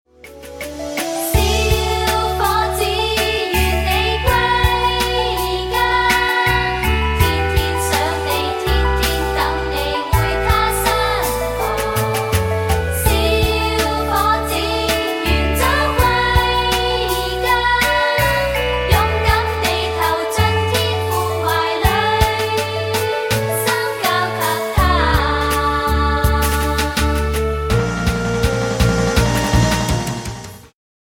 充滿動感和時代感
有伴奏音樂版本